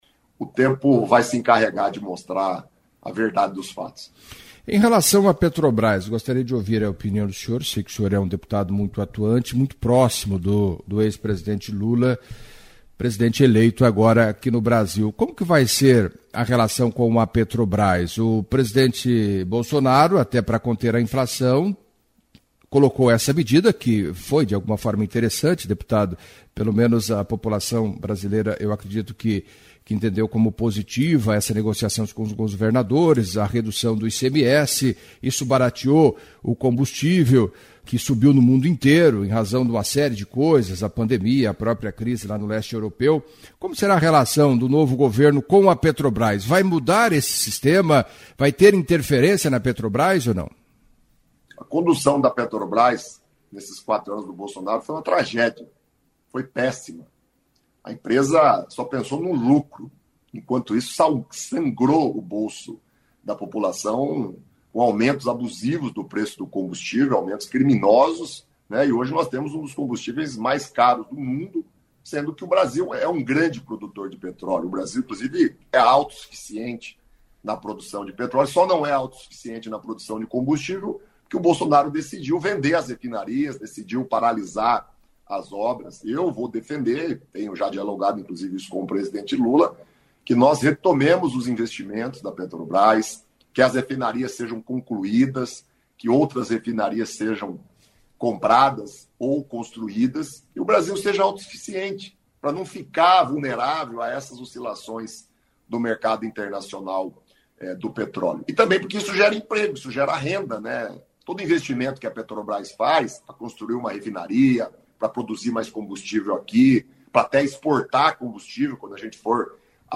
Em entrevista à CBN Cascavel nesta terça-feira (01), o deputado federal paranaense, Zeca Dirceu, do PT, entre outros temas, falou do bloqueio nas rodovias, o que espera do processo de transição de governo e destacou algumas ações do próximo presidente da República, Luiz Inácio Lula da Silva, principalmente, no Paraná, acompanhe.